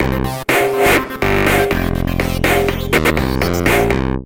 ちょっとしたメロディーっぽい音も欲しいなぁと思ったので、新たに Ultra Analog Session と FireBird というVSTiも使ってみました。
先ほどのリズムにベースとシンセ音を追加したフレーズ・サンプル（MP3）